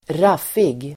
Ladda ner uttalet
Uttal: [²r'af:ig]
raffig.mp3